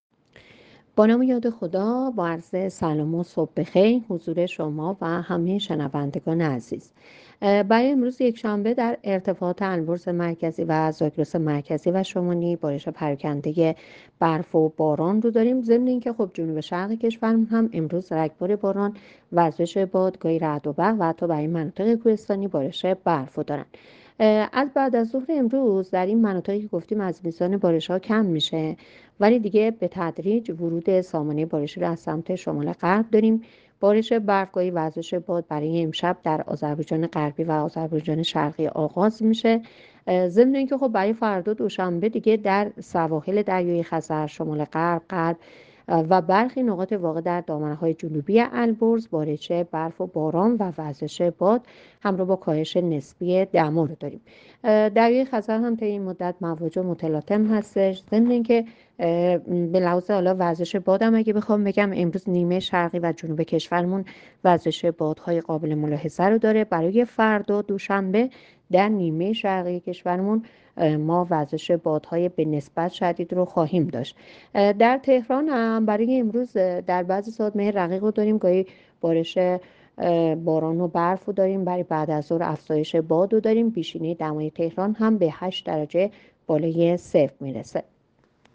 گزارش رادیو اینترنتی پایگاه‌ خبری از آخرین وضعیت آب‌وهوای ۳۰ دی؛